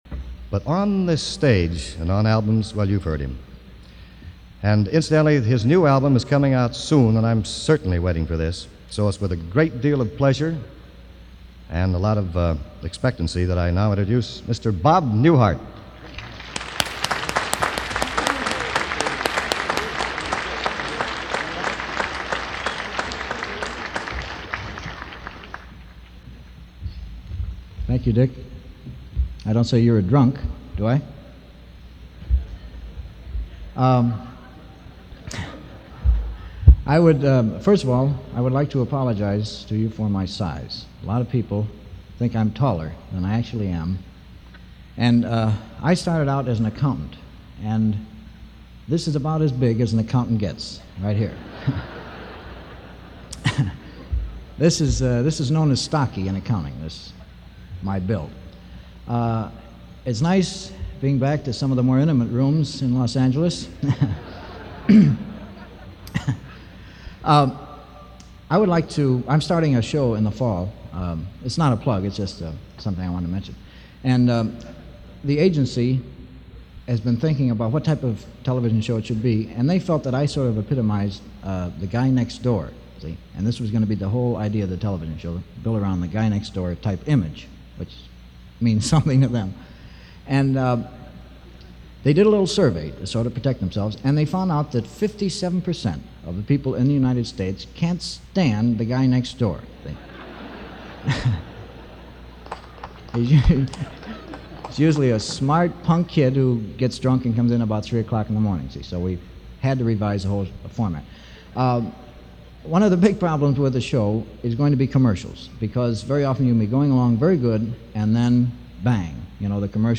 The legendary Bob Newhart, live at the Hollywood Bowl during the annual benefit for the Boys Club of America in 1961.
bob-newhart-hollywood-bowl-1961.mp3